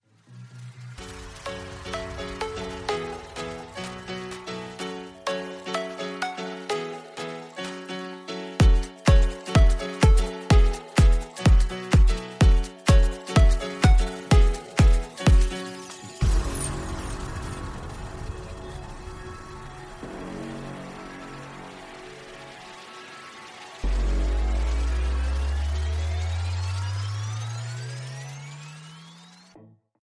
HOUSE  (2.25)